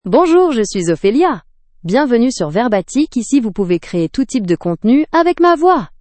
OpheliaFemale French AI voice
Ophelia is a female AI voice for French (France).
Voice sample
Listen to Ophelia's female French voice.
Ophelia delivers clear pronunciation with authentic France French intonation, making your content sound professionally produced.